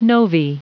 Prononciation du mot novae en anglais (fichier audio)